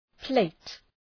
Προφορά
{pleıt}